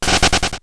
running.wav